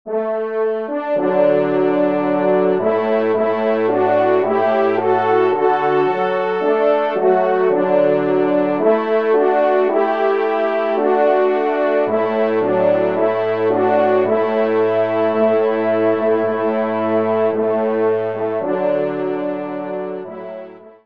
Genre : Musique Religieuse pour Trois Trompes ou Cors
ENSEMBLE